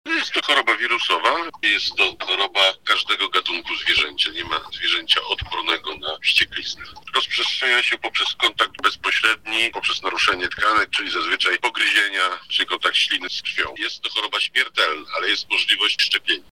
Wścieklizna rozprzestrzenia się poprzez kontakt bezpośredni, czyli na przykład pogryzienie – mówi Paweł Piotrowski, lubelski wojewódzki lekarz weterynarii: